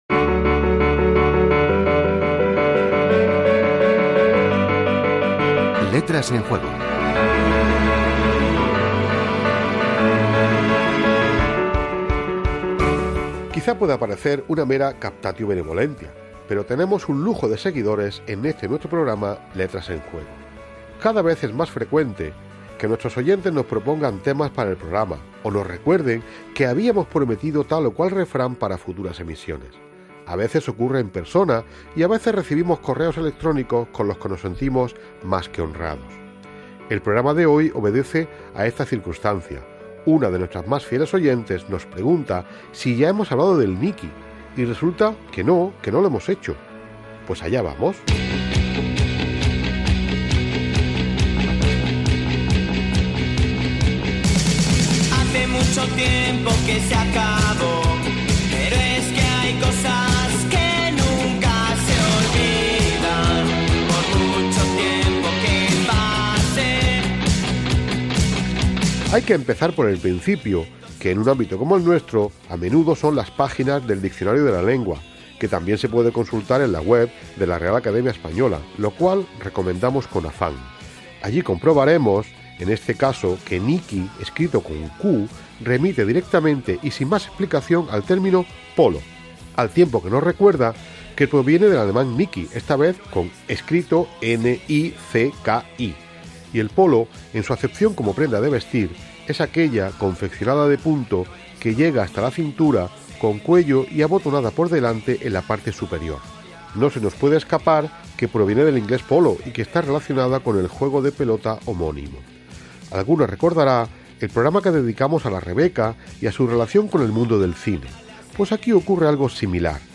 Careta del programa, presentació i espai dedicat a la paraula "niqui" que és una altra manera de denominar a la peça de vestir polo
Entreteniment